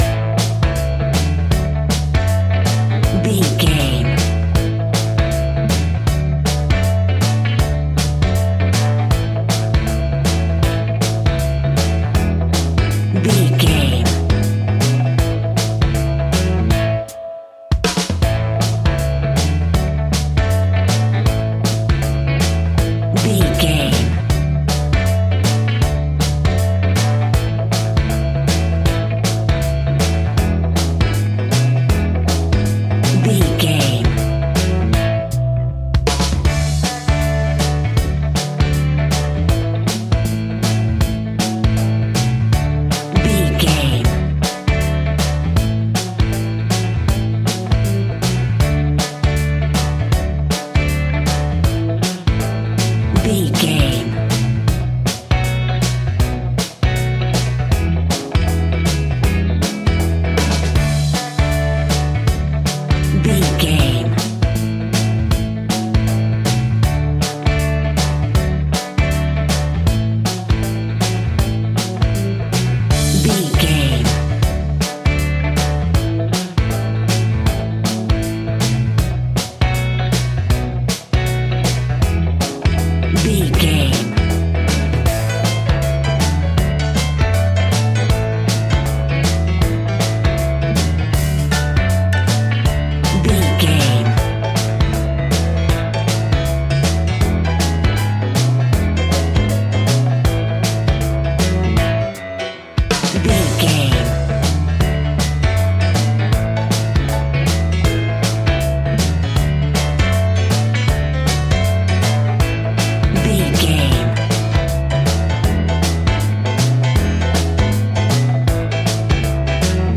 Ionian/Major
uplifting
bass guitar
electric guitar
drums
cheerful/happy